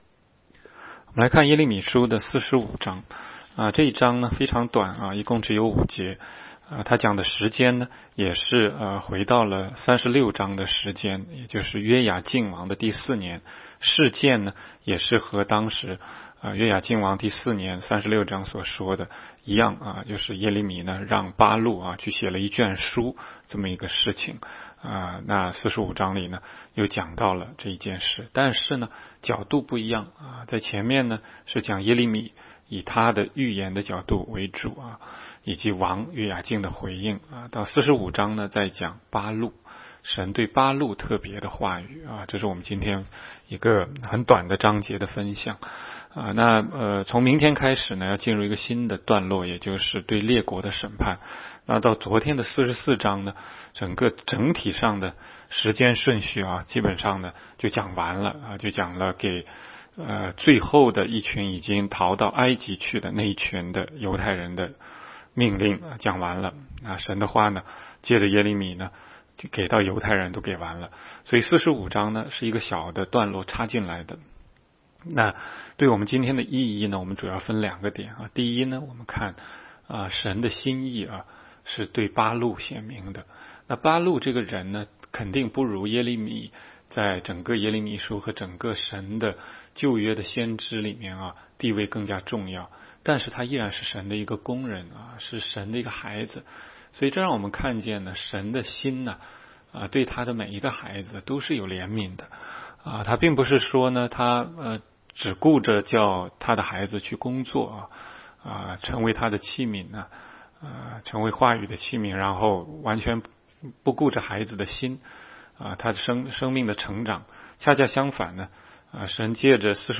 16街讲道录音 - 每日读经 -《耶利米书》45章